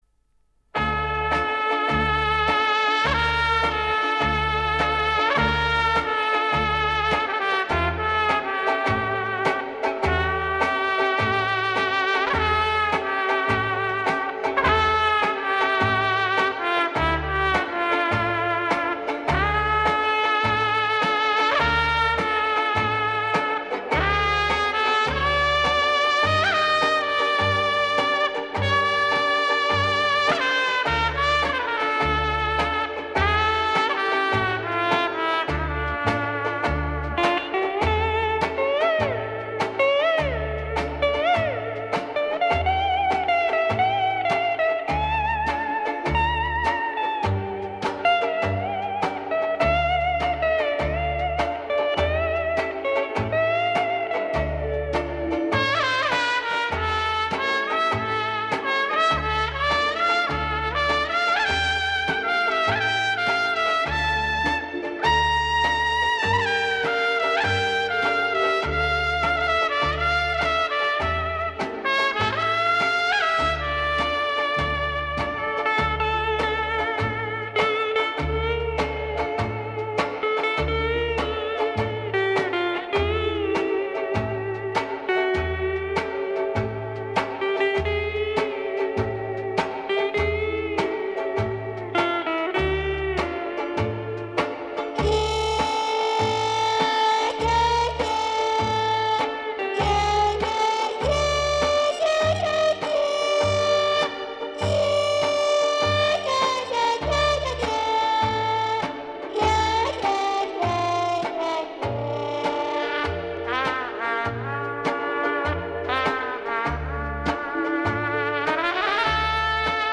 Запись была неважная, солировали труба и гитара.